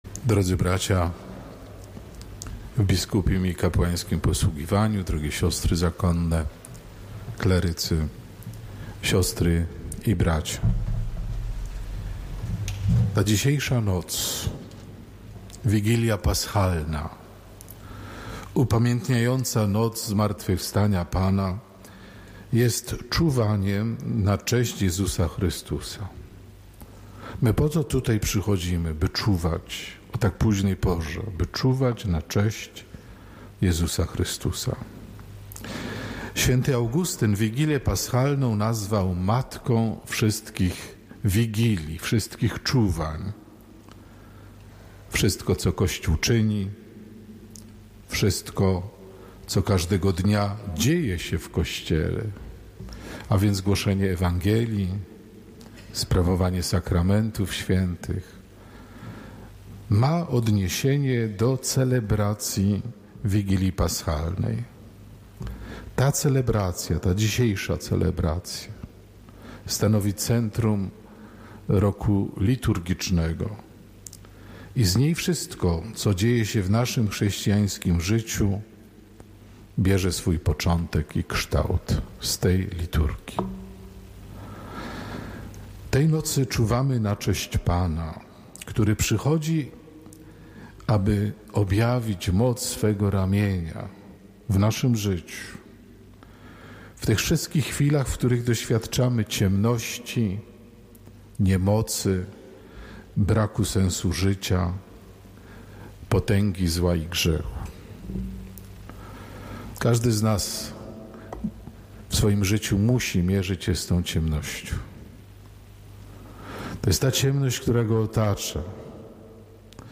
Wigilia Paschalna w Katedrze Wrocławskiej [HOMILIA]
Abp Józef Kupny, Metropolita Wrocławski, przewodniczył transmitowanej przez Radio Rodzina z katedry wrocławskiej Liturgii Wigilii Paschalnej. W koncelebrze uczestniczyli biskupi pomocniczy – bp Jacek Kiciński oraz bp Maciej Małyga.